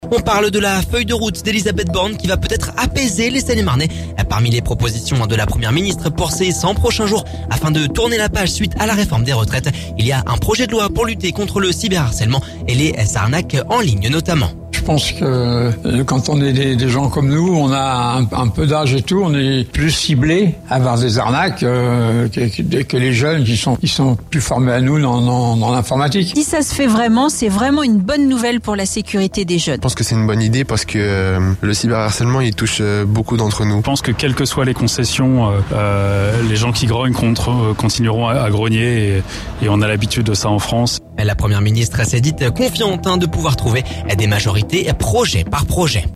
Une information qui ne satisfait pas tous les Seine-et-Marnais intérrogés...